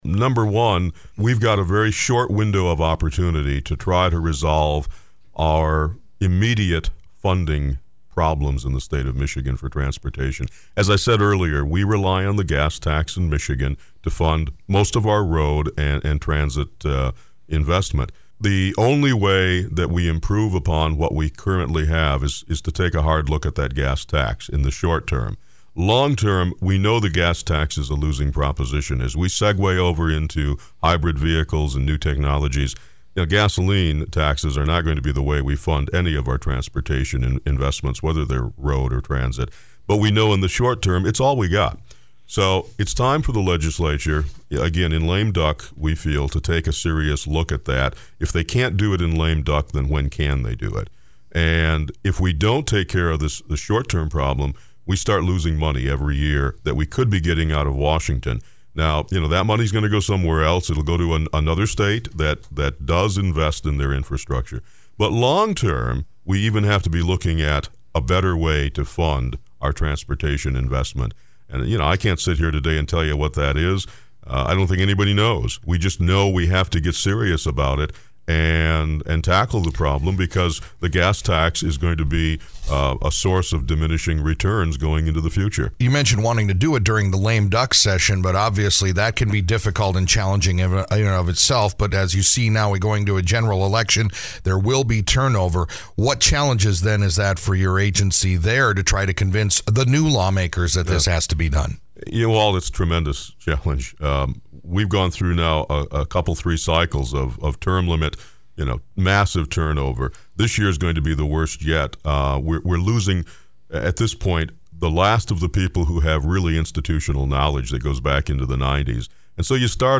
Former radio guy and State Legislator Clark Harder, who is now the Executive Director of the Michigan Public Transit Association, joined the show to give us a re-cap of a special round-table discussion that was held yesterday in Marquette looking at issues in funding and maintaining public transportation services around the Upper Peninsula as well as around the entire state. He outlined the challenges in the gas-tax based funding scheme that create the financial crises that are faced every day by the member agencies.